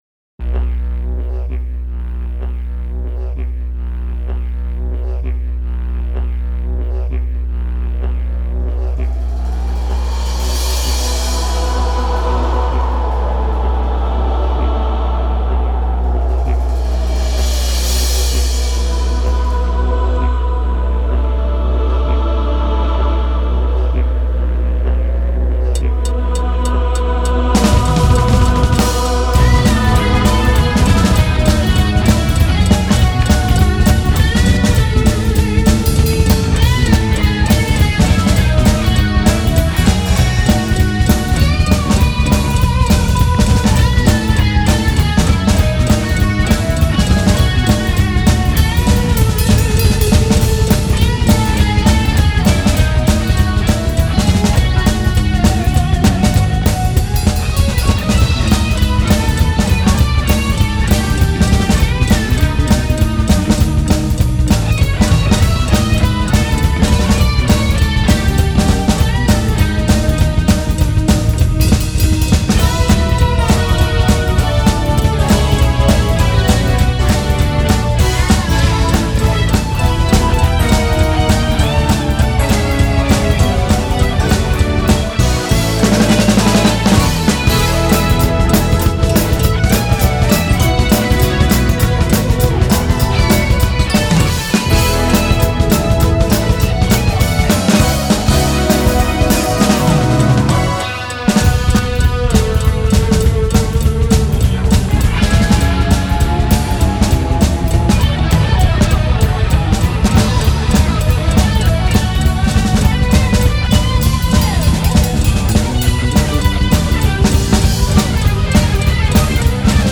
ROCK SHUFFLE 6-4.mp3